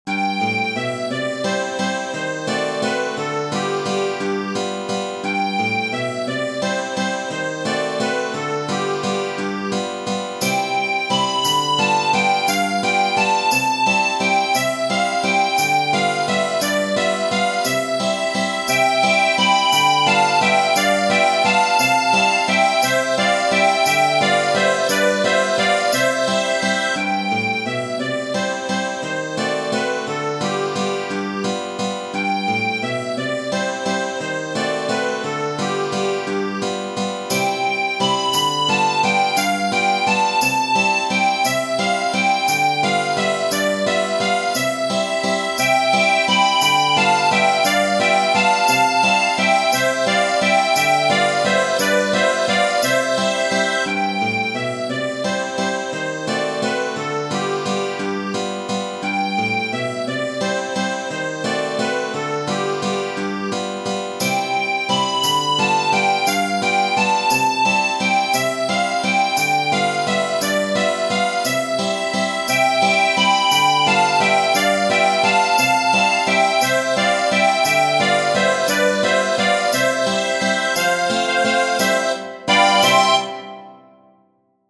Tradizionale Genere: Ballabili Amor dammi quel fazzolettino, amor dammi quel fazzolettino, amor dammi quel fazzolettino, vado alla fonte, lo vado a lavar.